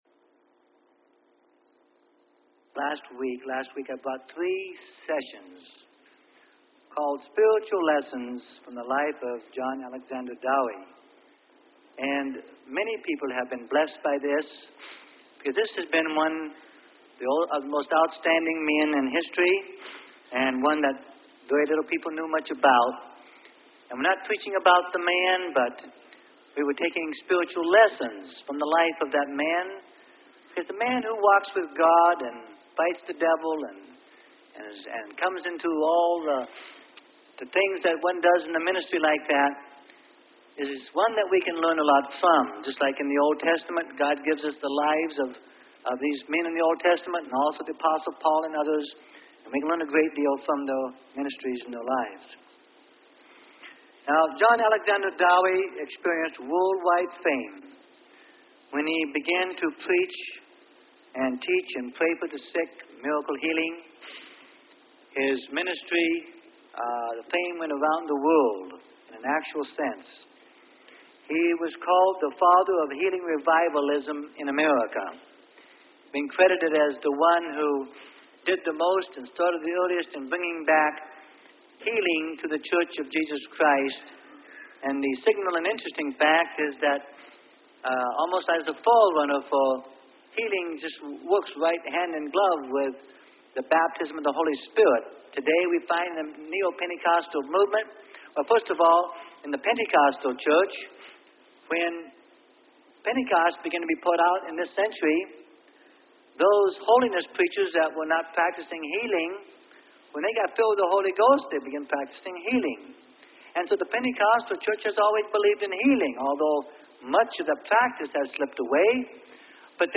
Sermon: Spiritual Lessons From The Life Of John Dowie - Part 4 Of 4 - Freely Given Online Library